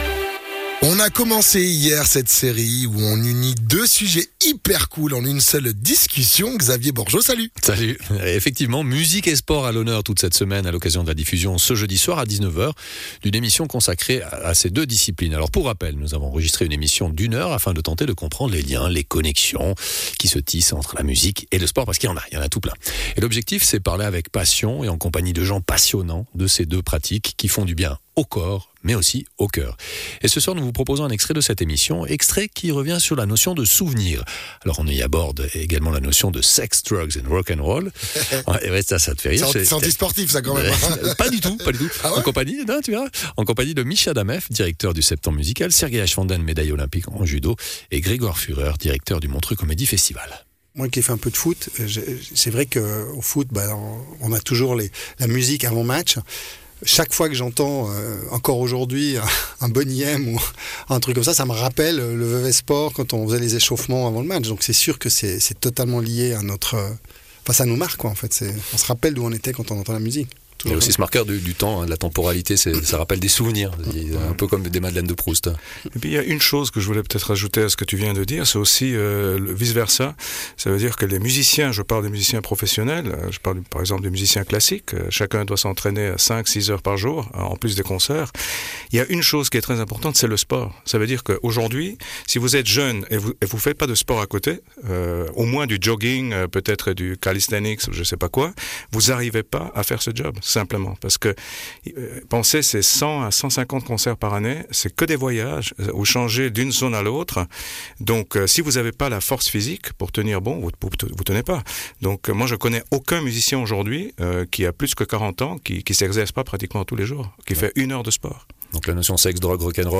Sergei Aschwanden, Médaillé Olympique en judo